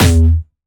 Jumpstyle Kick 3
12 E2.wav